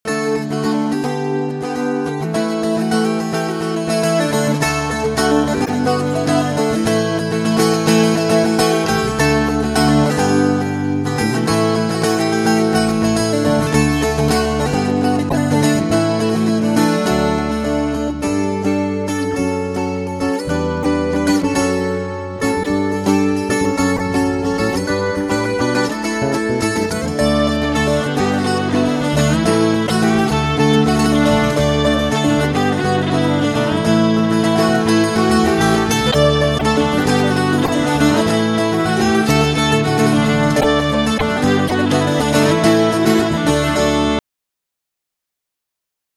dulcimer acoustique